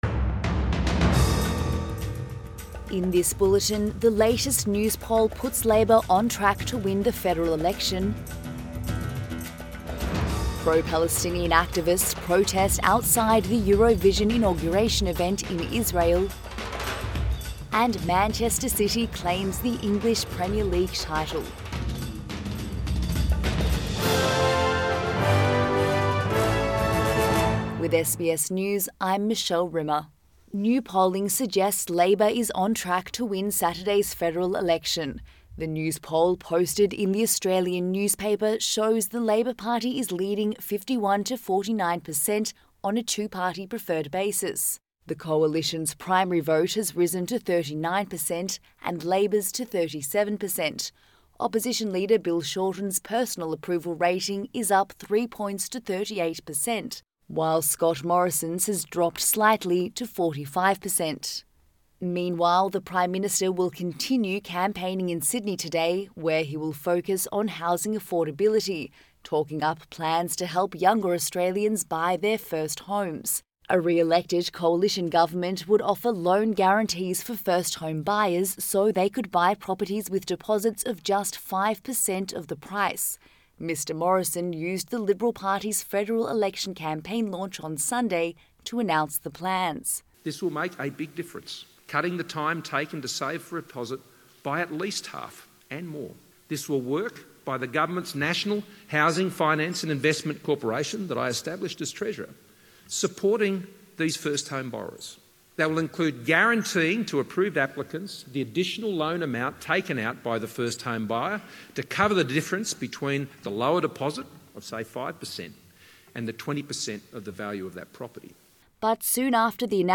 AM Bulletin May 13